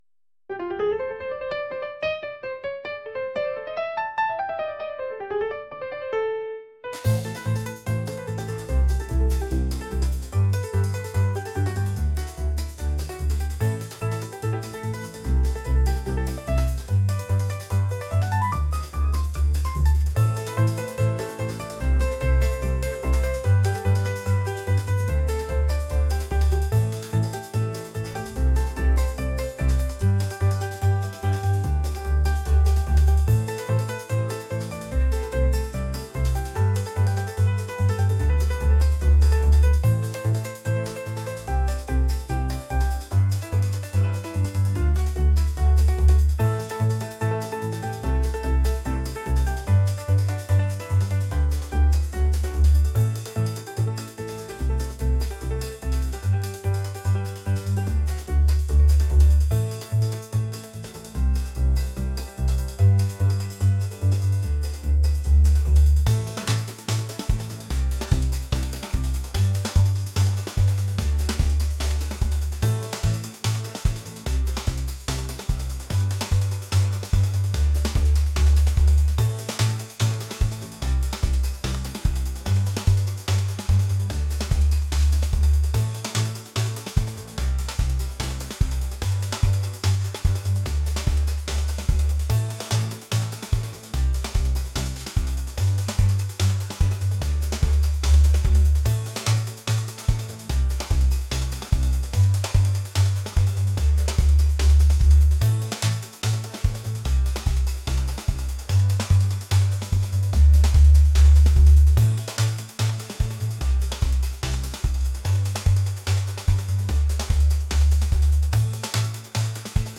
energetic | jazz